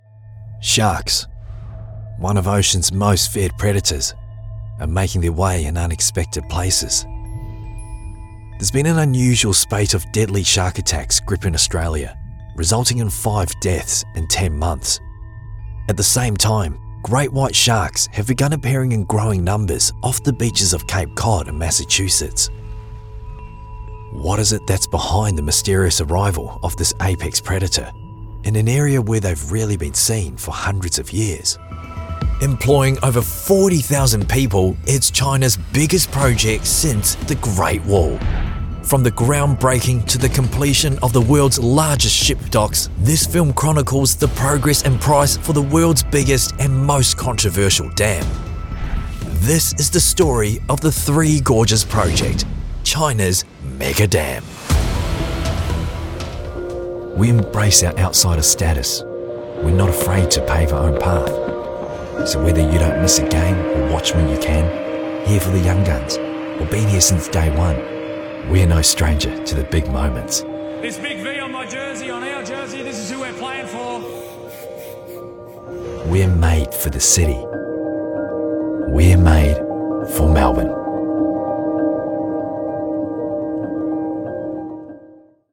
Male
Adult (30-50), Older Sound (50+)
Professional Australian and New Zealand male voice artist and actor with over 10 years performance experience on stage, film and opera, tailoring authenticity to your message.
Documentary/Story Narration
All our voice actors have professional broadcast quality recording studios.